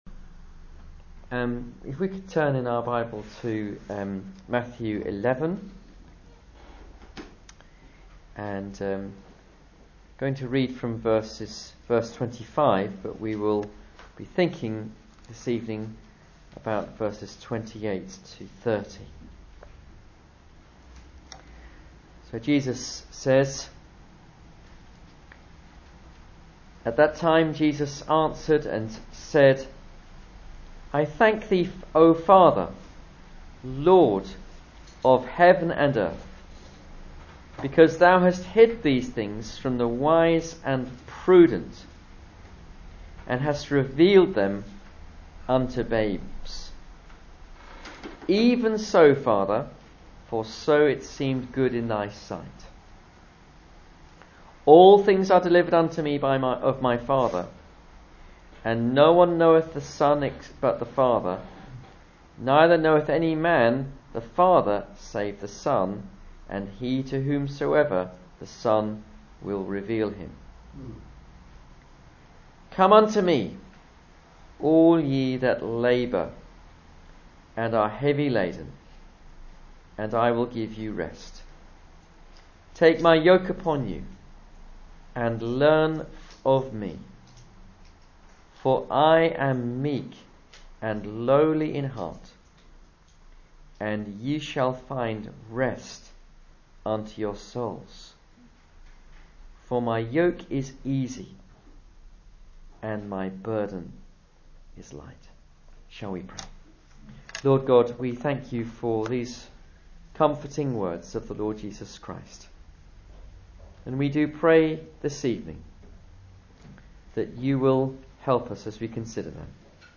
Service Type: Sunday Evening Service